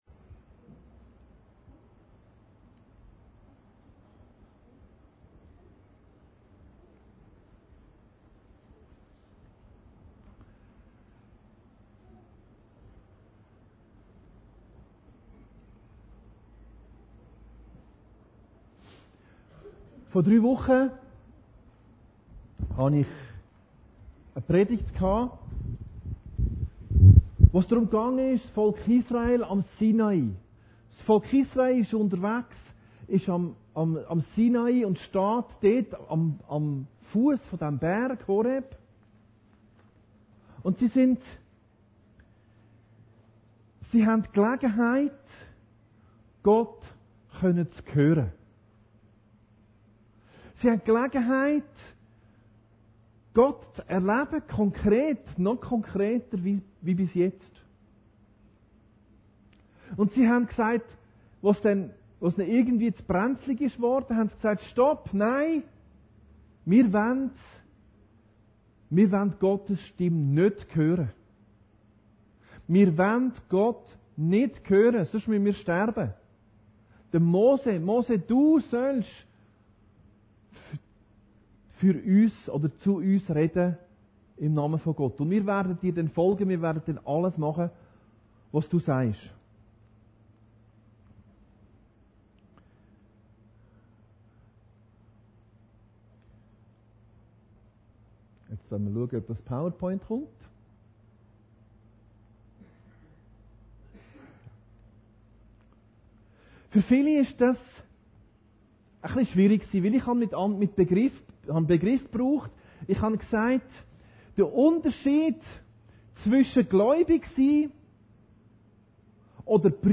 Predigten Heilsarmee Aargau Süd – so_sy_wie_jesus Predigten der Heilsarmee Aargau Süd Home Predigten Aktuelle Seite: Startseite Predigten so_sy_wie_jesus ↑↑↑ Donnerstag, 21.